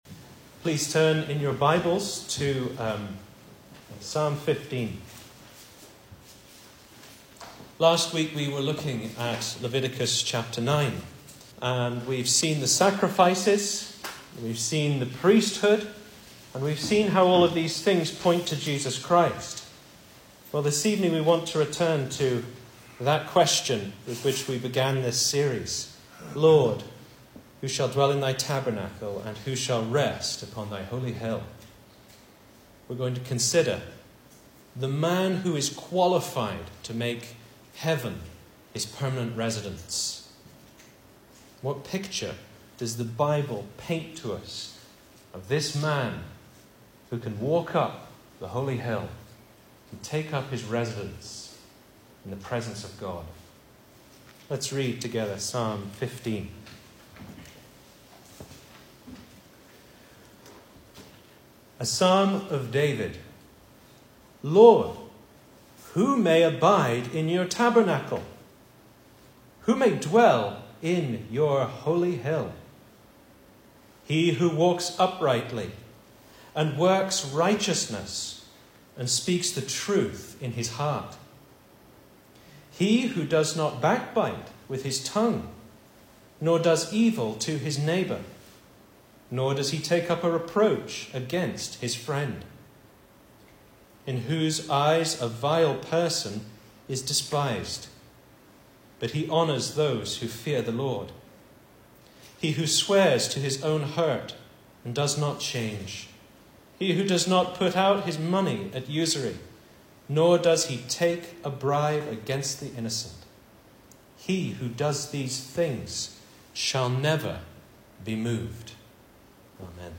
2024 Service Type: Sunday Evening Speaker